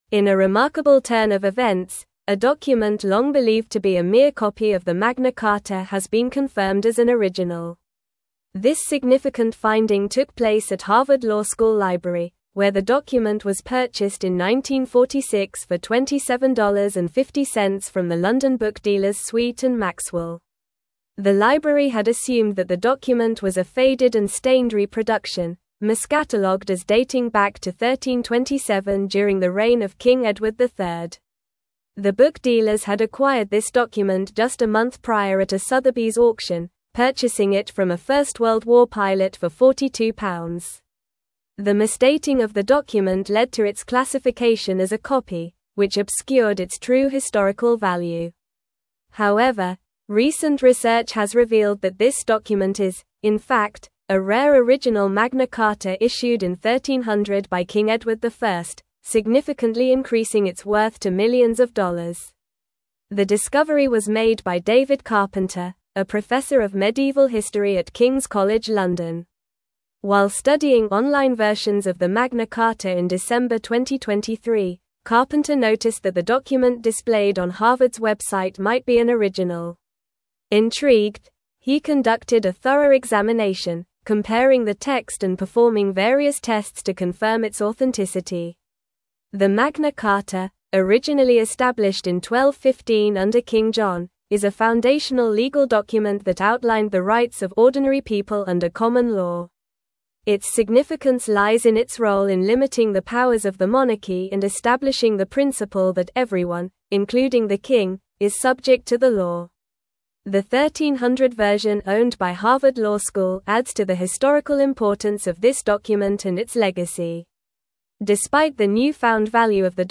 Normal
English-Newsroom-Advanced-NORMAL-Reading-Harvard-Library-Confirms-Original-Magna-Carta-Discovery.mp3